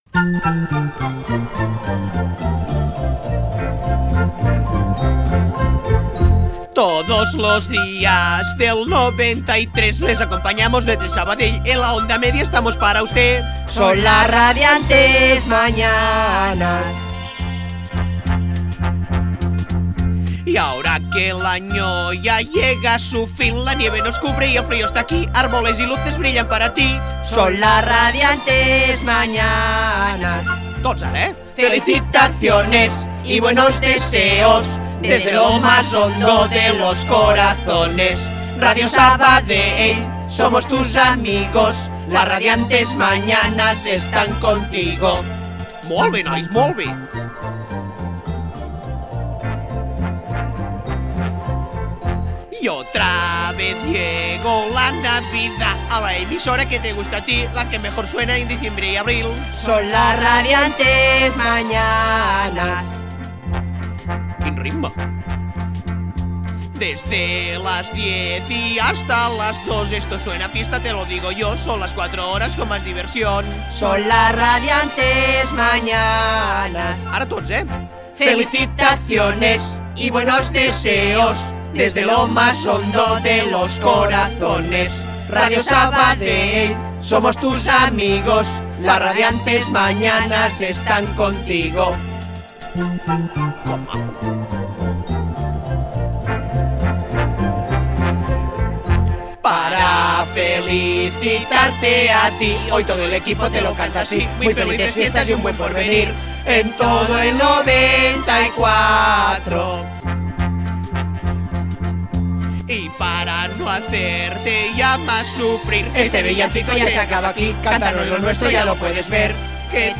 Nadala del programa
Entreteniment